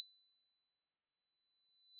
Sound 3 – Sinusoid 4000Hz